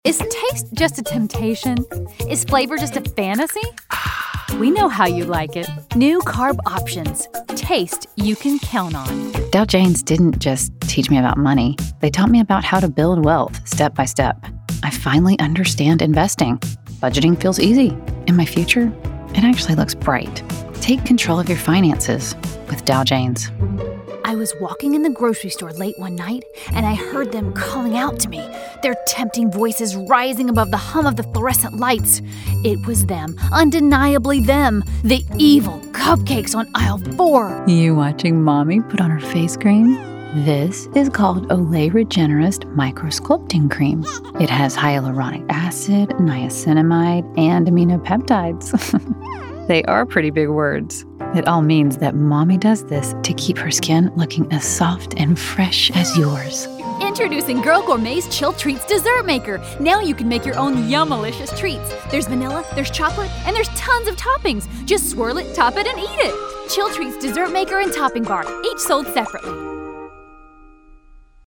VO Demo